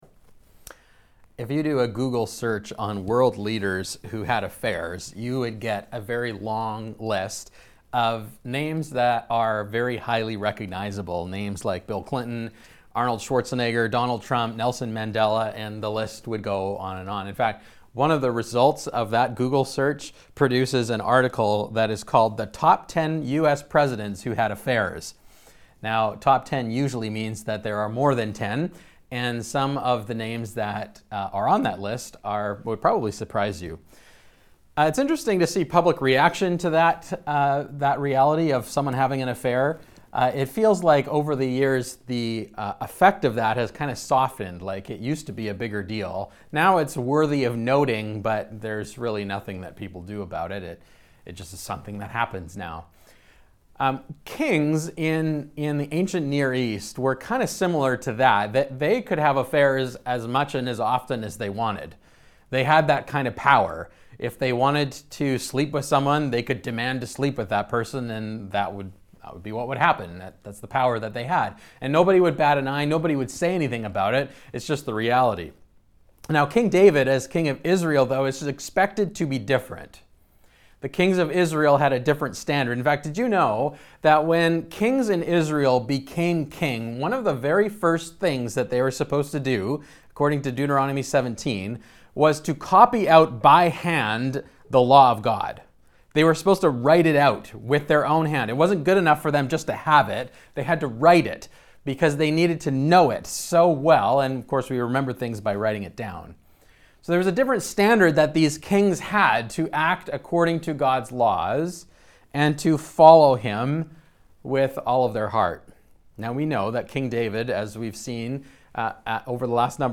Ross Road Community Church - Podcast | Ross Road Community Church